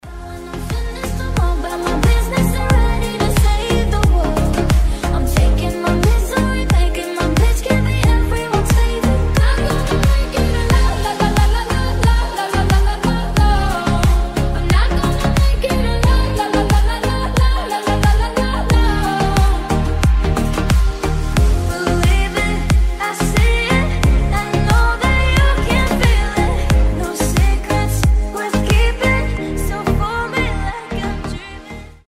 Cover
Mashup